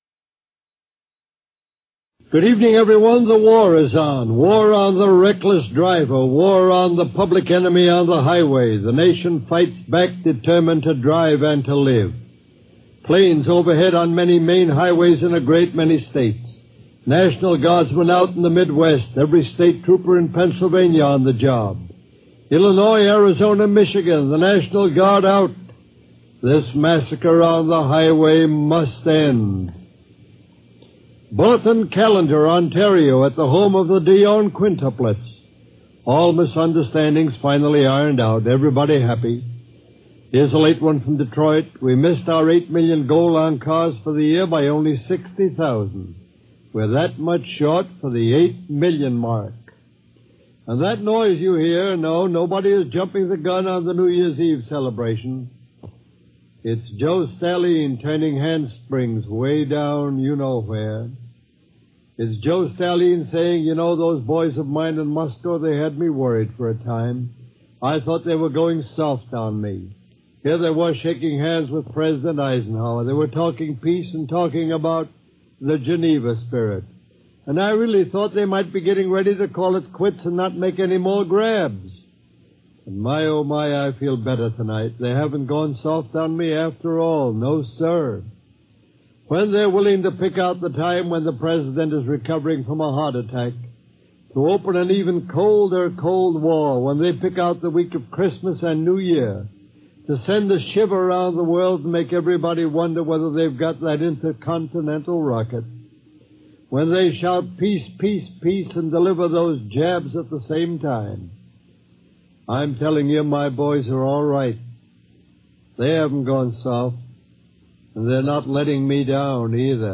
1955-12-31 MBS KHJ News